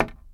BoxHit.mp3